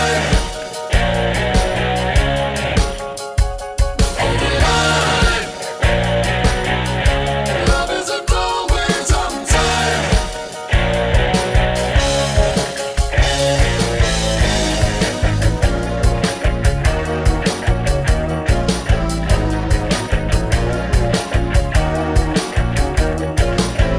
Soft Rock